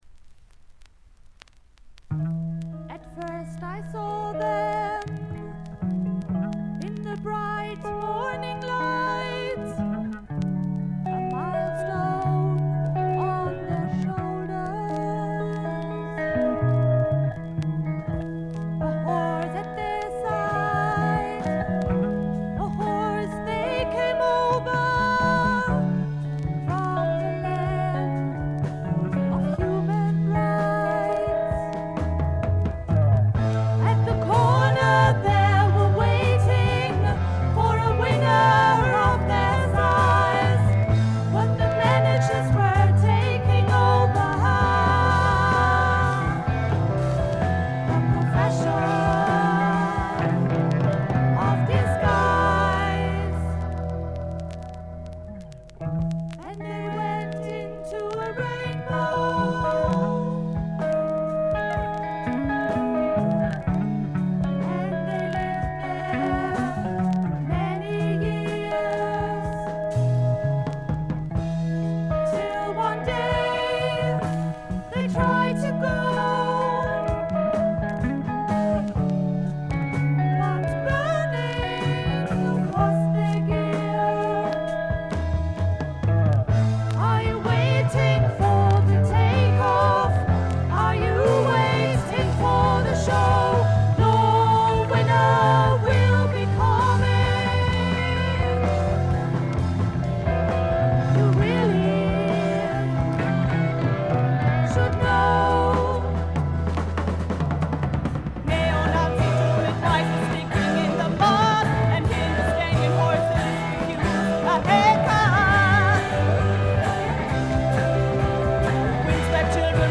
全体にバックグラウンドノイズが出ていますが静音部で分かる程度。散発的なプツ音が2-3回ほど。
ジャーマン・アンダーグラウンド・プログレッシヴ・サイケの雄が放った名作。
試聴曲は現品からの取り込み音源です。